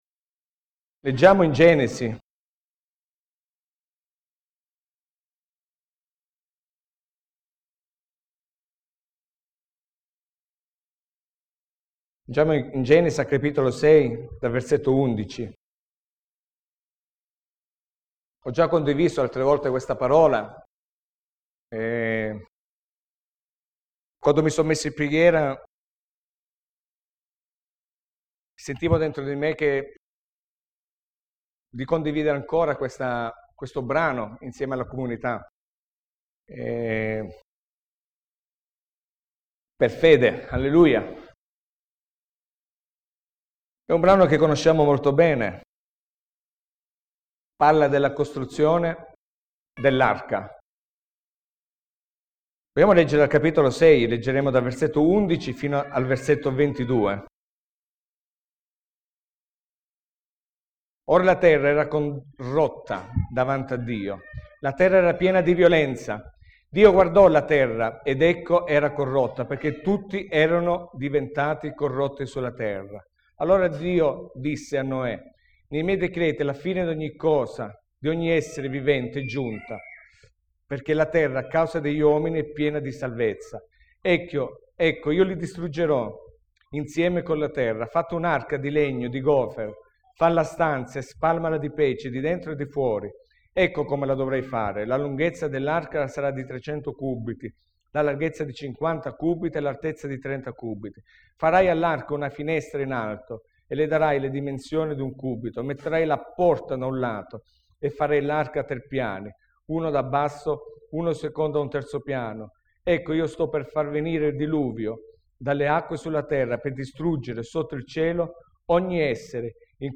Predicazione 01 settembre 2013 - La salvezza mediante la santificazione dello Spirito